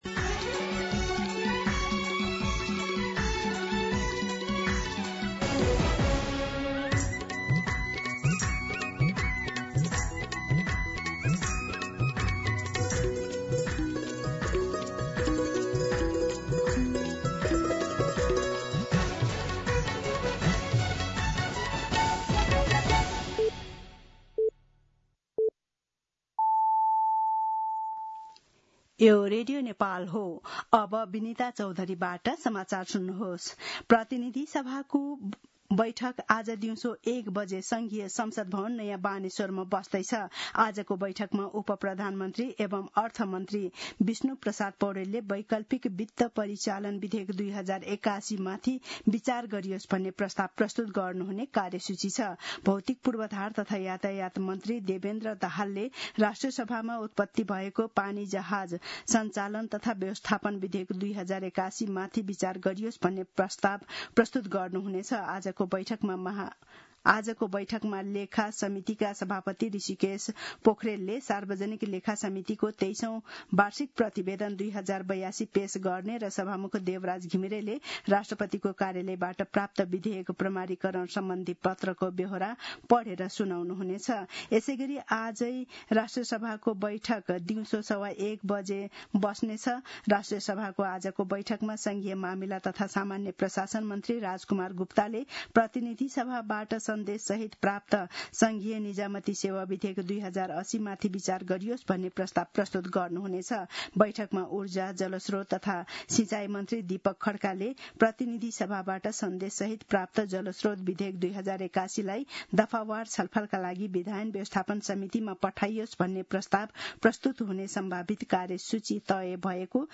मध्यान्ह १२ बजेको नेपाली समाचार : ३१ असार , २०८२
12pm-News-31.mp3